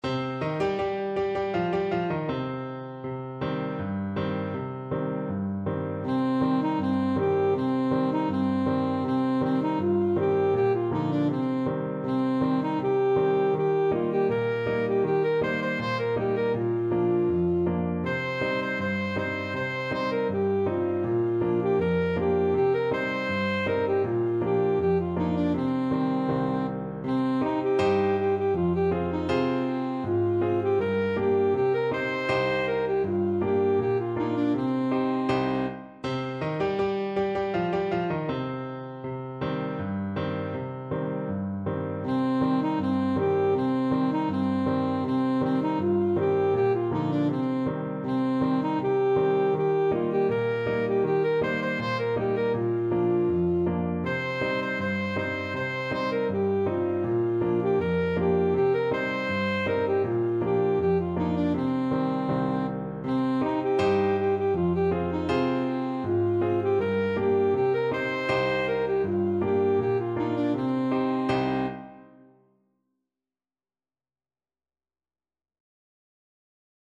Traditional Trad. Du Shu Lang (The Little Schoolboy) Alto Saxophone version
World Asia China Du Shu Lang (The Little Schoolboy)
Alto Saxophone
C minor (Sounding Pitch) A minor (Alto Saxophone in Eb) (View more C minor Music for Saxophone )
Pesante =c.80
2/4 (View more 2/4 Music)
Traditional (View more Traditional Saxophone Music)